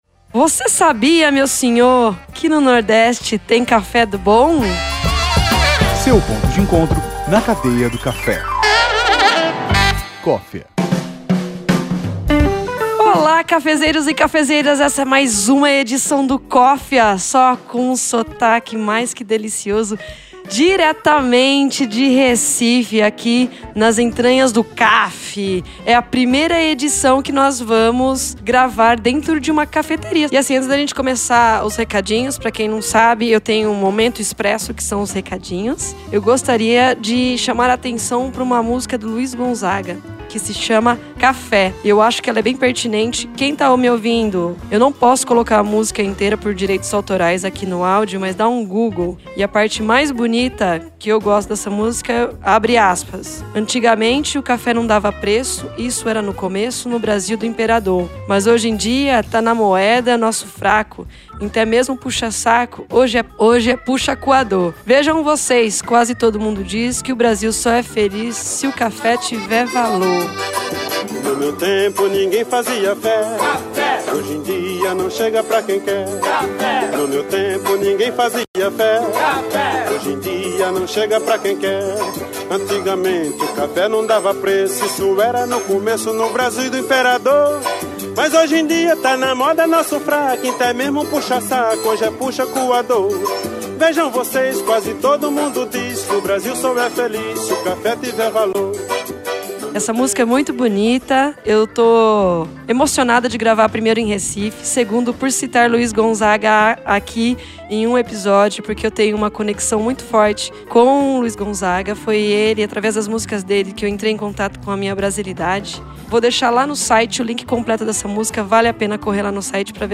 nos emprestam conhecimento num sotaque delicioso de se ouvir.